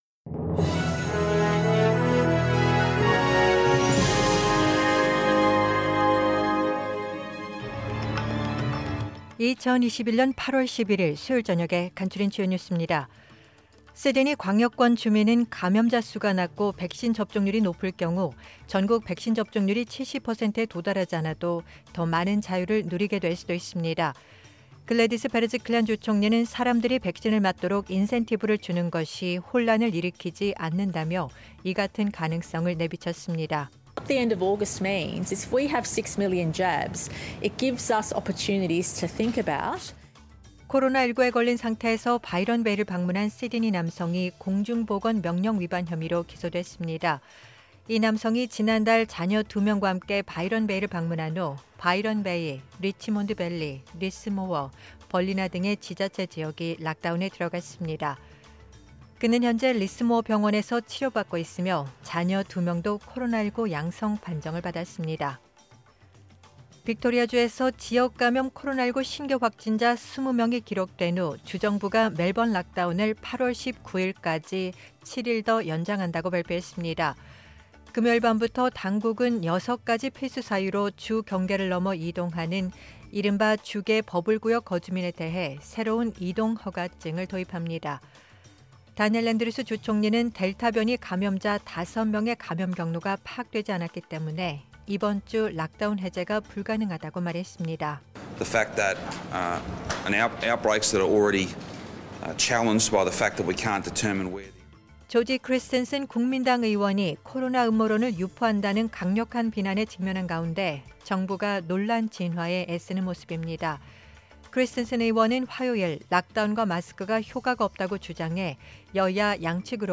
2021년 8월 11일 수요일 저녁의 SBS 뉴스 아우트라인입니다.